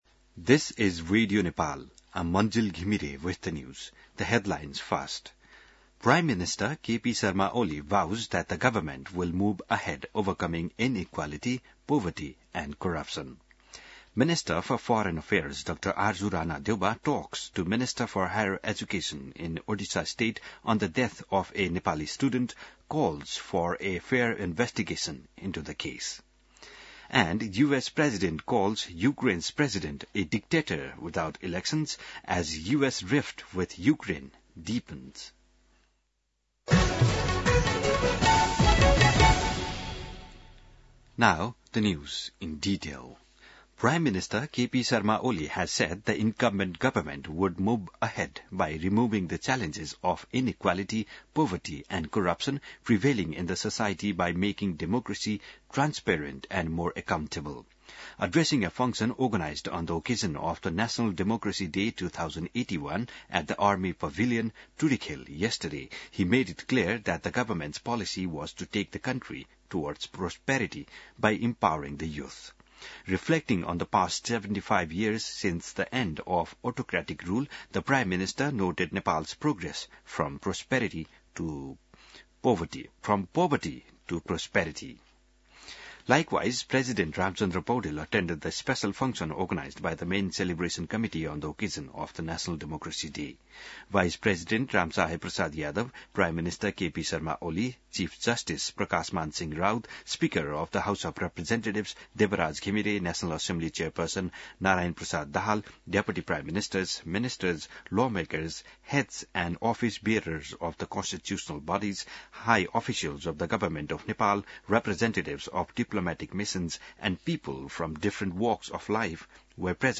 बिहान ८ बजेको अङ्ग्रेजी समाचार : ९ फागुन , २०८१